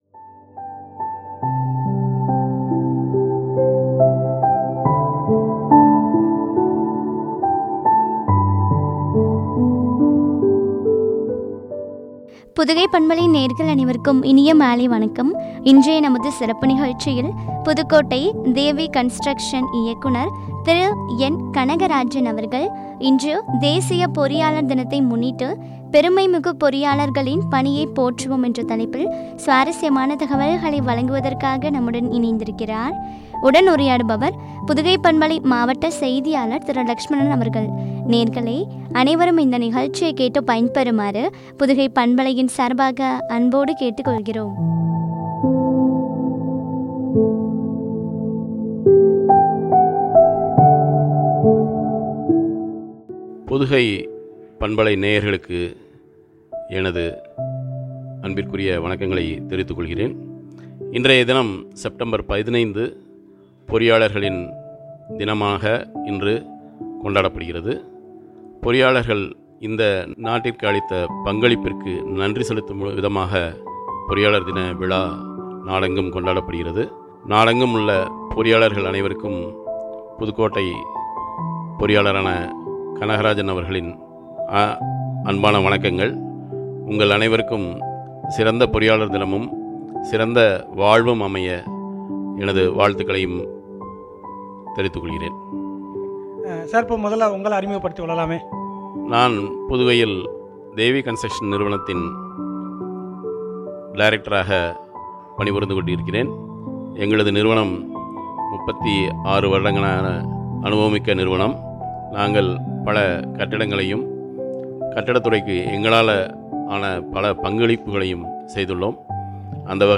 ” பெருமைமிகு பொறியாளர்களின் பணி போற்றுவோம்” குறித்த வழங்கிய உரையாடல்.